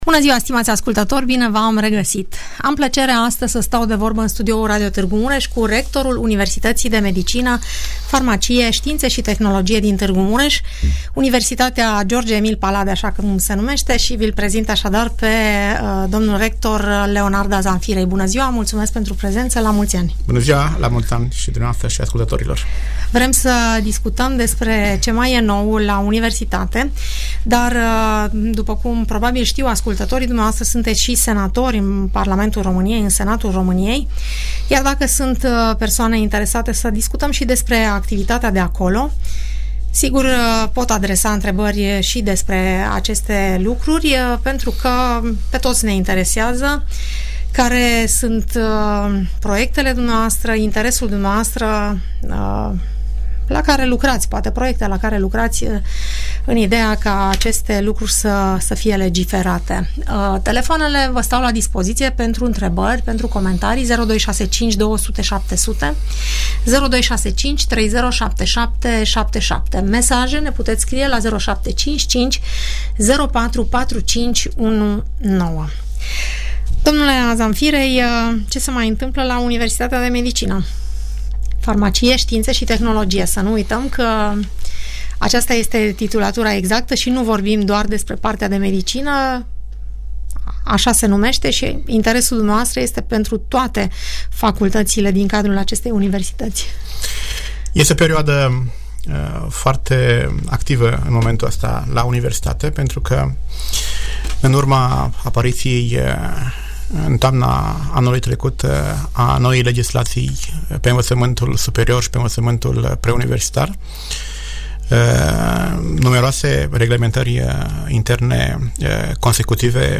vorbește în emisiunea „Părerea ta” despre marile proiecte care vizează dezvoltarea universității.